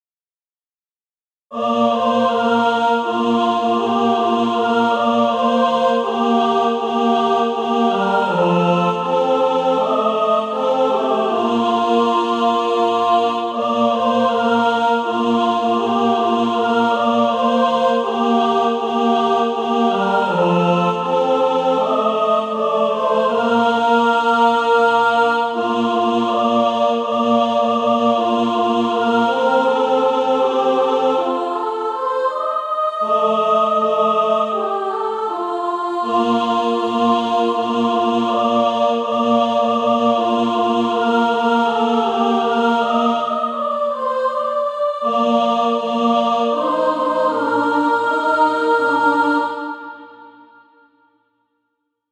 (SATB) Author